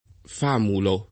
famulo [ f # mulo ] s. m.